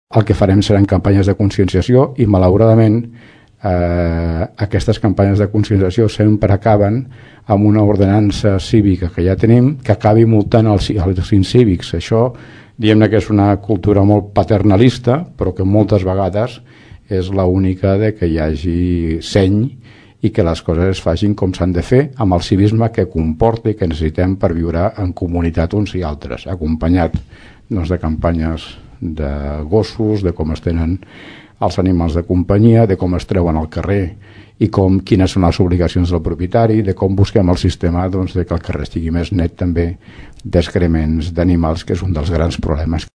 L’Alcalde insisteix que hi haurà campanyes de conscienciació, per tal de millorar les actituds incíviques a Tordera. Recorda que aquestes campanyes sempre acaben amb sancions econòmiques perquè la gent reaccioni.